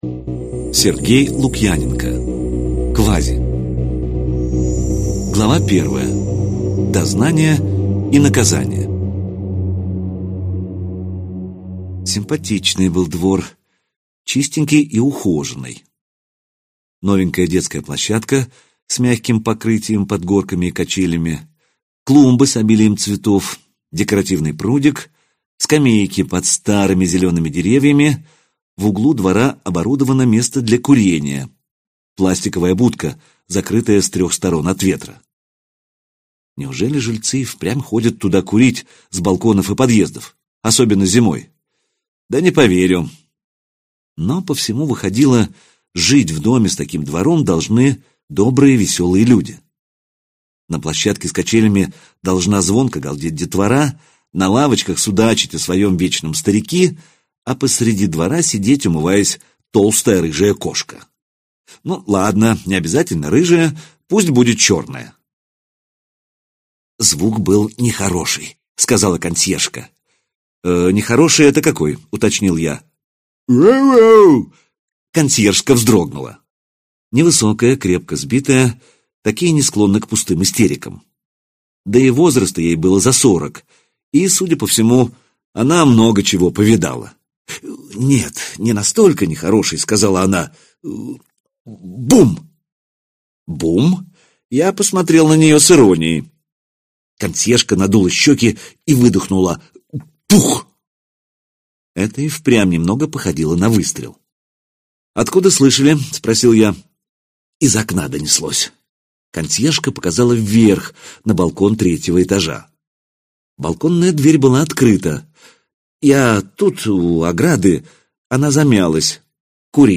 Аудиокнига Z: Квази. Кайнозой | Библиотека аудиокниг